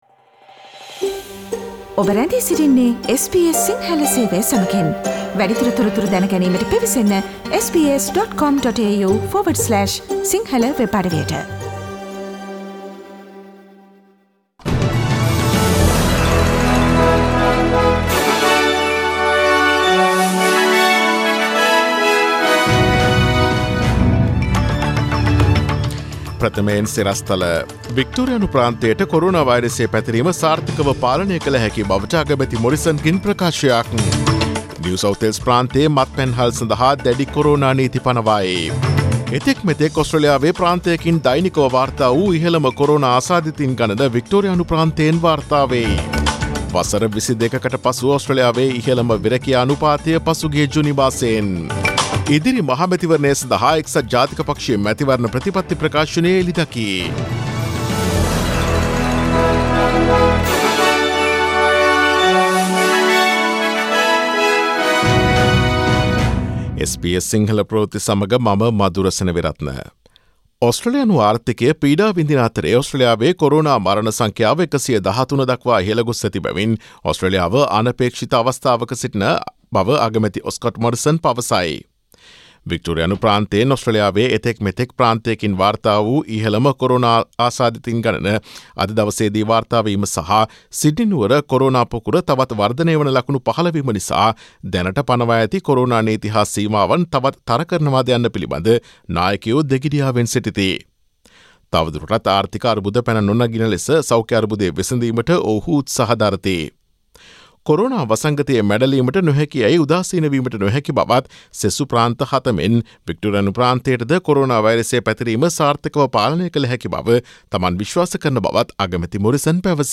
Daily News bulletin of SBS Sinhala Service: Friday 17 July 2020
Today’s news bulletin of SBS Sinhala Radio – Friday 17 July 2020 Listen to SBS Sinhala Radio on Monday, Tuesday, Thursday and Friday between 11 am to 12 noon